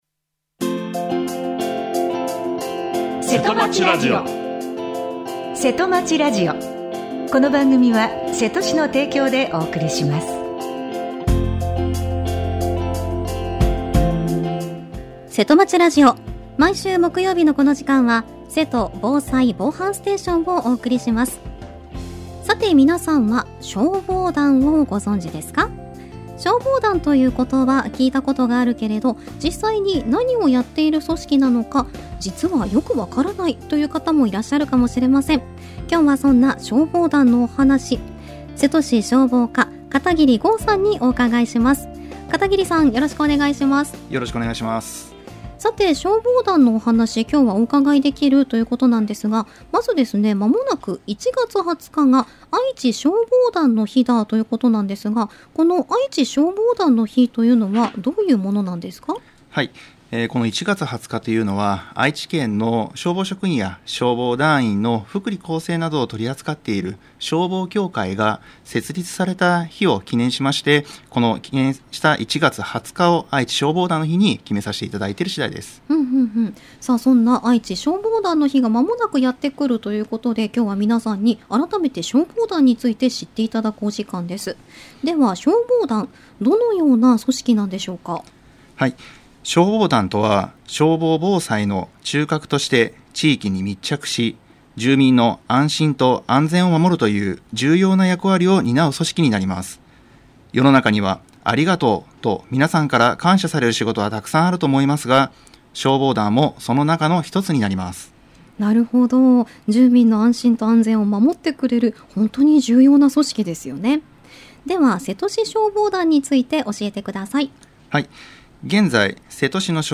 についてお話を伺いました。